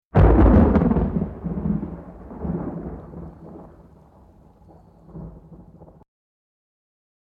自然界
雷（116KB）